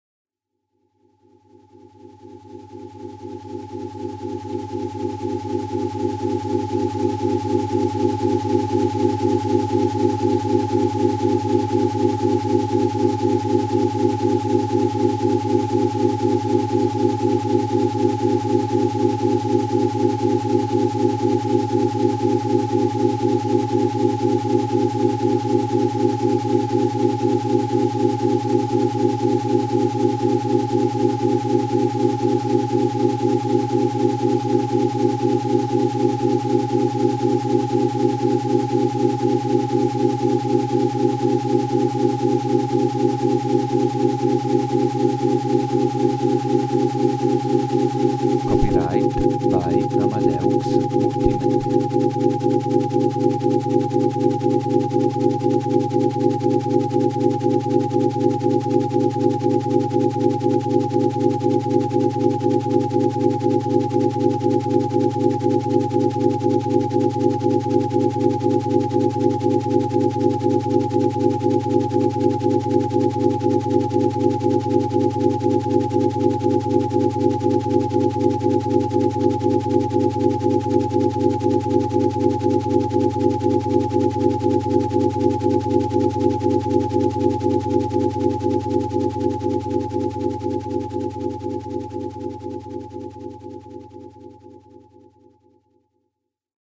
18.AT014-serotinin2-asmr-demo
AT014-serotinin2-asmr-demo.mp3